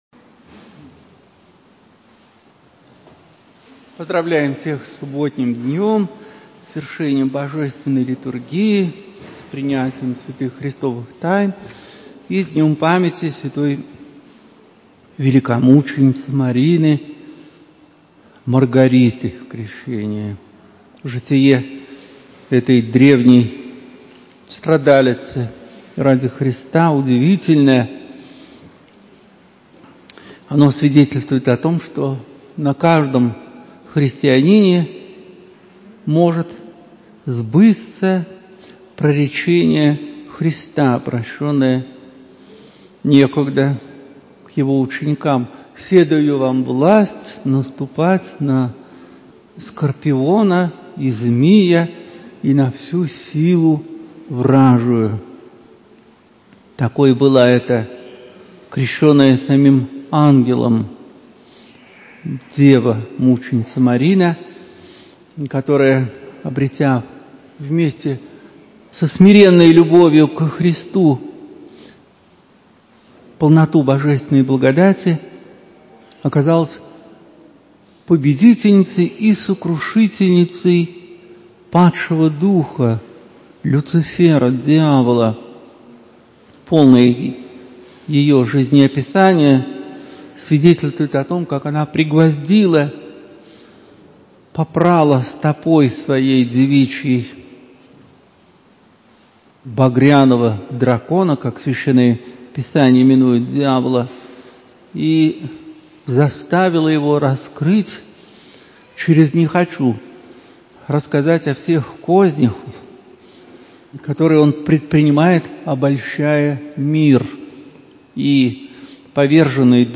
Проповедь
В храме Всех Святых ставропигиального женского Алексеевского монастыря.